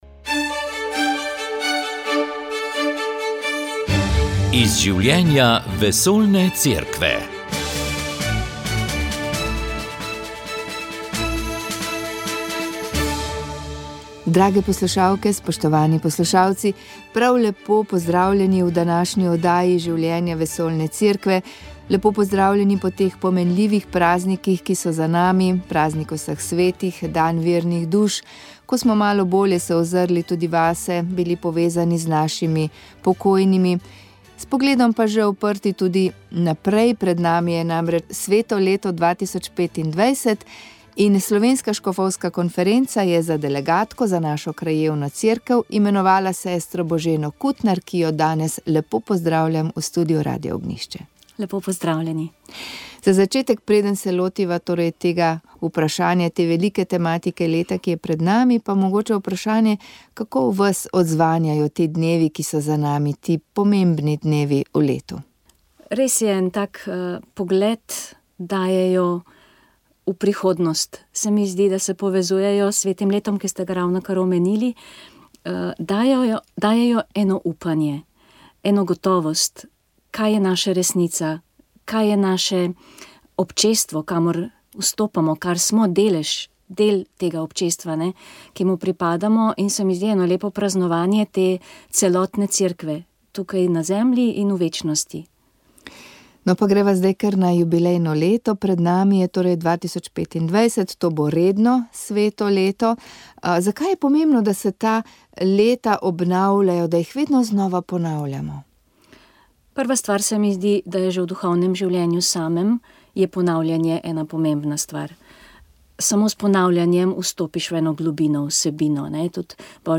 O tem so spregovorili na ponedeljkovi novinarski konferenci na Družini, kjer so predstavili papeževo avtobiografijo z naslovom Življenje. Za vas pa smo v oddaji pripravili tudi druge novice iz življenja Cerkve v tem tednu.